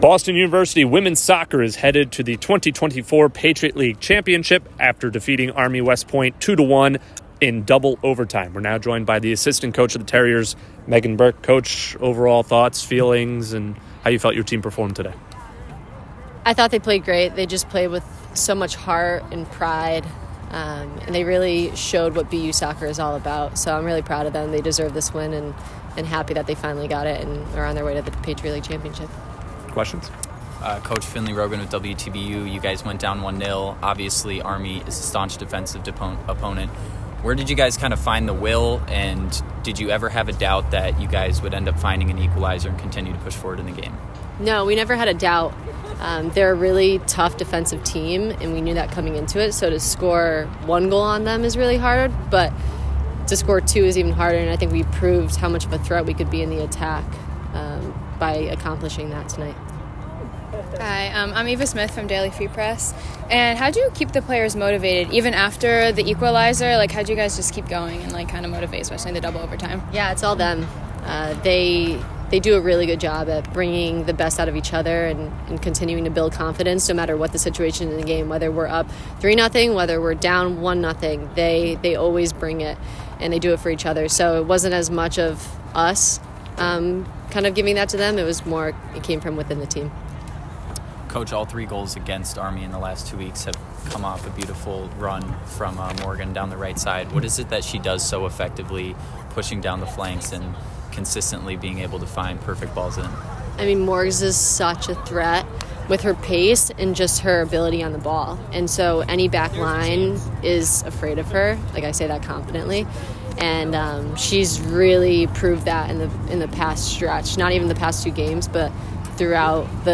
Women's Soccer / Patriot League Semifinal Postgame Interviews